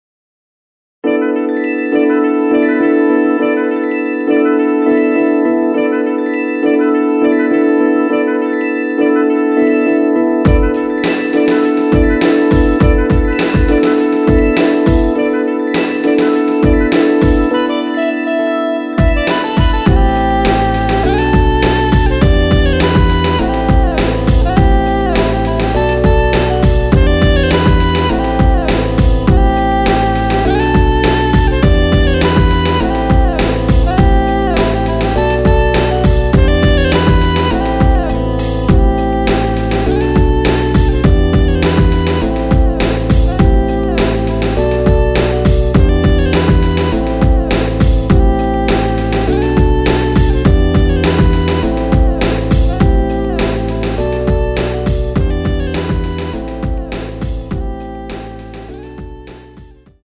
Am
앞부분30초, 뒷부분30초씩 편집해서 올려 드리고 있습니다.
중간에 음이 끈어지고 다시 나오는 이유는